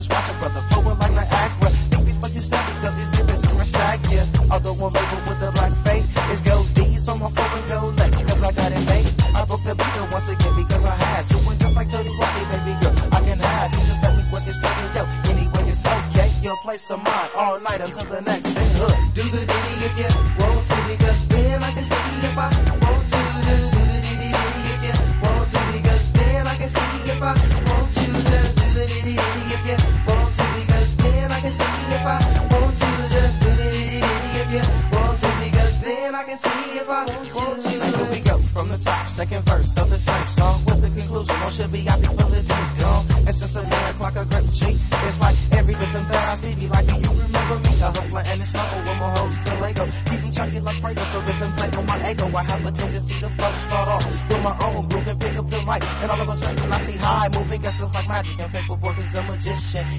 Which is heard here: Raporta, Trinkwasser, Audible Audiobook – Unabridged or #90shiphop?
#90shiphop